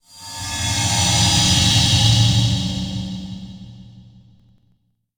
teleport3.wav